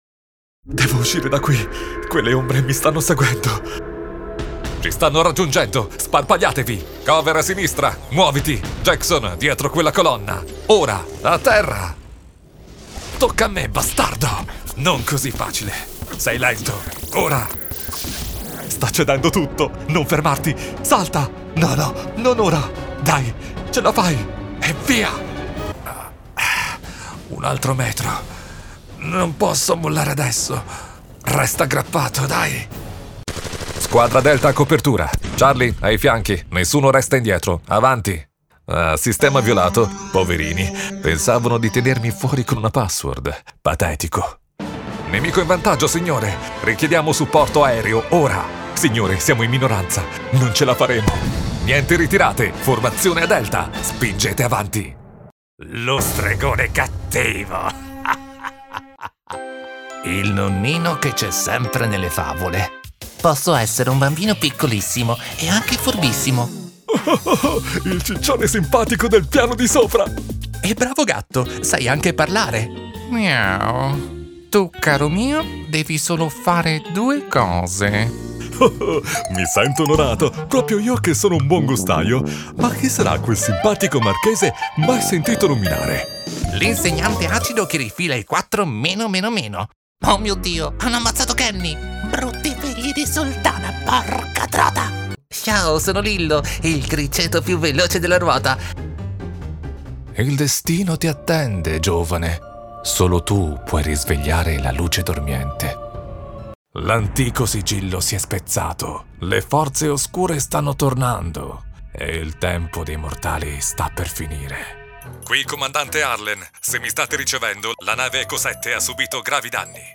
Yng Adult (18-29) | Adult (30-50)